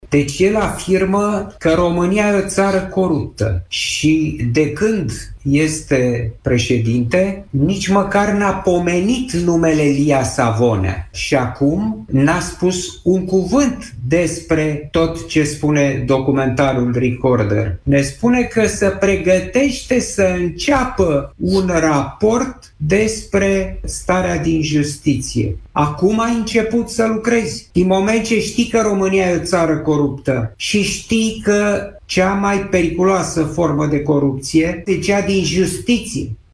Într-o intervenție la Europa FM, acesta a condamnat actualul sistem de justiție și a salutat protestele de aseară.